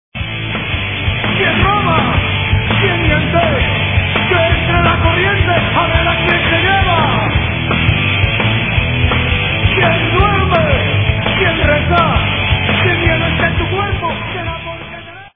Escucha un fragmento de ese directo, en Mp3